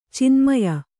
♪ cinmaya